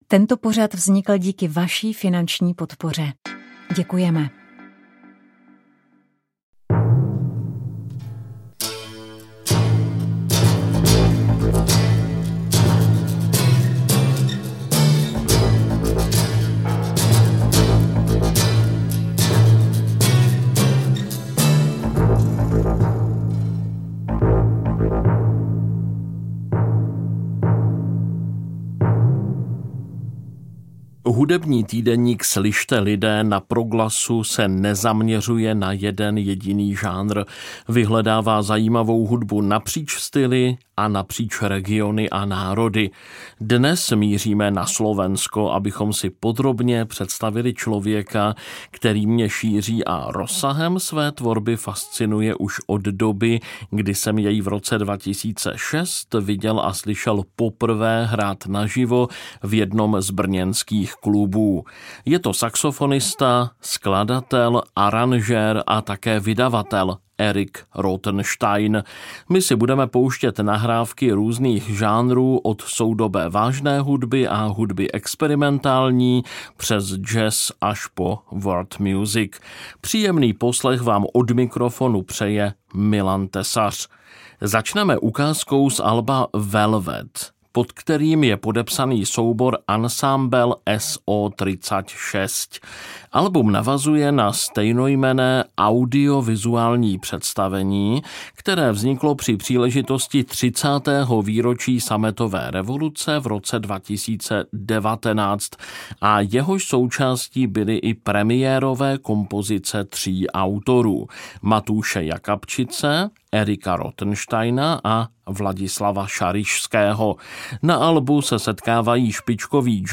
V tomto vydání týdeníku Slyšte, lidé! vám nabídneme novinky z pestré hudební scény Finska. Představíme si hráče na finské lidové nástroje kantele a jouhikko, uslyšíte hudbu severských Sámů i švédskojazyčné menšiny ve Finsku. Těšit se můžete i na finský jazz nebo na interprety, kteří finskou hudbu propojují s hudbou Blízkého východu nebo Balkánu.